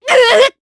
Glenwys-Vox_Damage_jp_03.wav